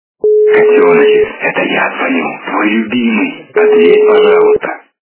» Звуки » Люди фразы » ¬®¬е¬Ш¬г¬Ь¬а¬Ы ¬Ф¬а¬Э¬а¬г - ¬¬¬а¬д¬Ц¬Я¬а¬й¬Ц¬Ь, ¬п¬д¬а ¬с ¬Щ¬У¬а¬Я¬р, ¬д¬У¬а¬Ы ¬Э¬р¬Т¬Ъ¬Ю¬н¬Ы. ¬°¬д¬У¬Ц¬д¬о, ¬б¬а¬Ш¬С¬Э¬е¬Ы¬г¬д¬С.
При прослушивании ¬®¬е¬Ш¬г¬Ь¬а¬Ы ¬Ф¬а¬Э¬а¬г - ¬¬¬а¬д¬Ц¬Я¬а¬й¬Ц¬Ь, ¬п¬д¬а ¬с ¬Щ¬У¬а¬Я¬р, ¬д¬У¬а¬Ы ¬Э¬р¬Т¬Ъ¬Ю¬н¬Ы. ¬°¬д¬У¬Ц¬д¬о, ¬б¬а¬Ш¬С¬Э¬е¬Ы¬г¬д¬С. качество понижено и присутствуют гудки.